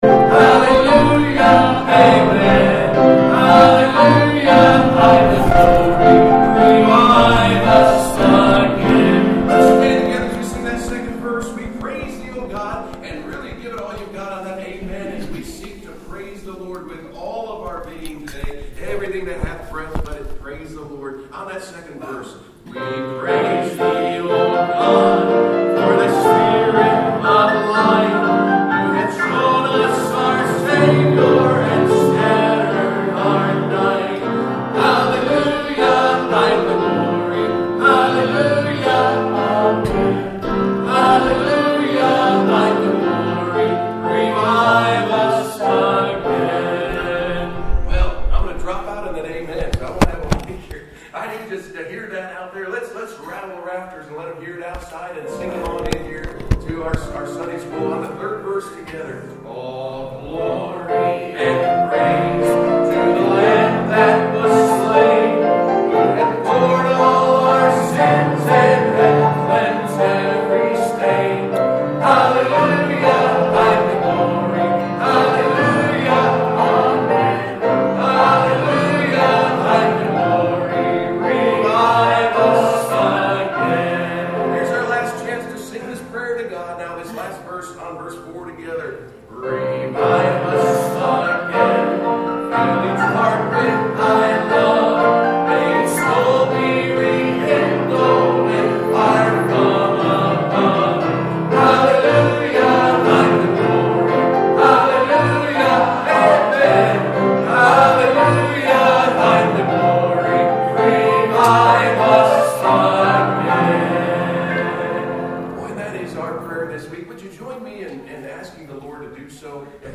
Sunday School
Evangelist